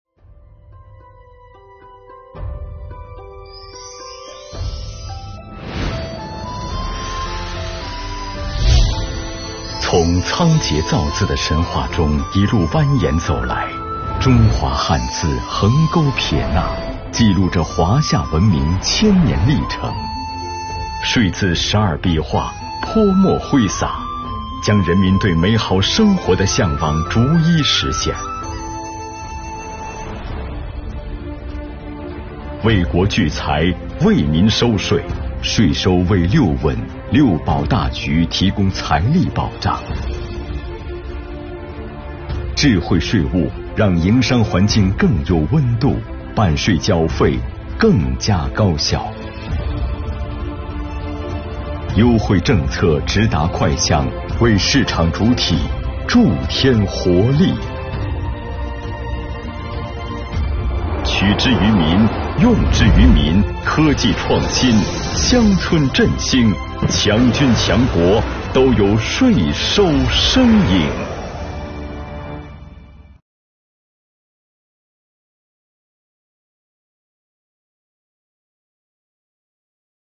作品拍摄手法专业，画面唯美，气势恢宏的音乐渲染带给读者较强的感染力。